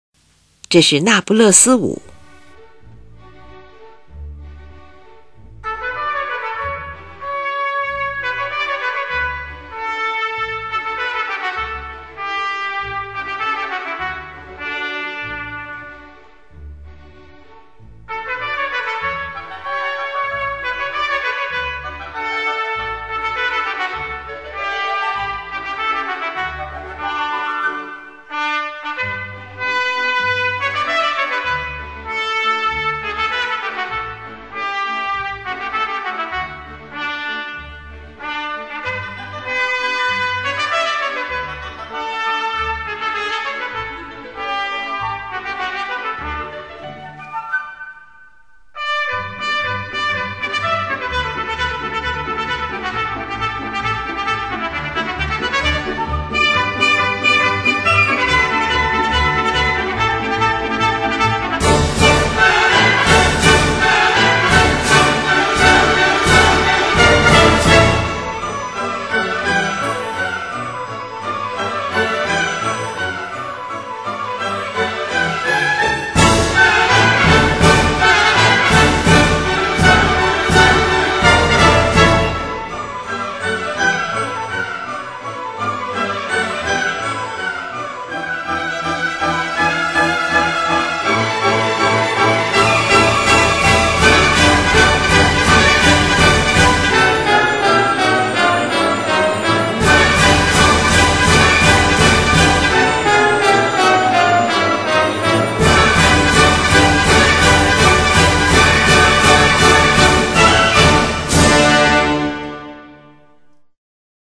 整个舞曲以小号为主奏，音乐活泼，前半段平稳，后半段则节奏越来越快，气氛越来越热烈，是一首塔兰泰拉风俗舞曲。